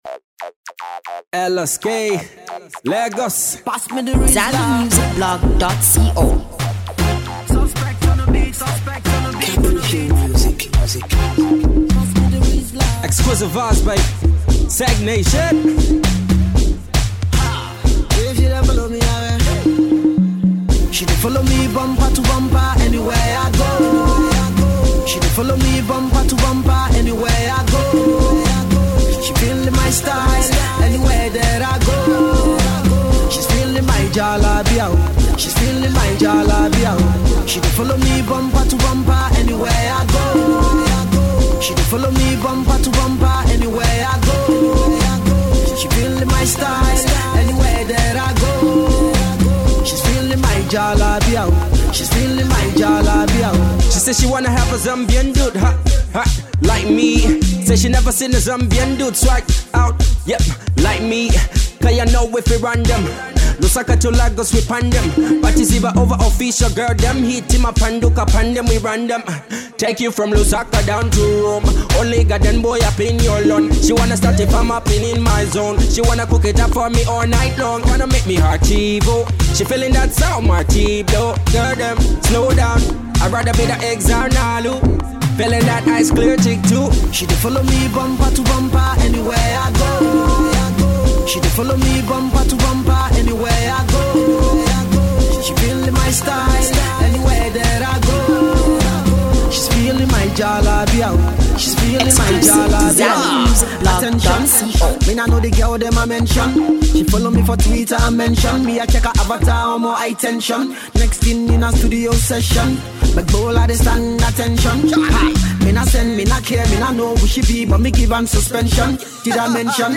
Dance hall tune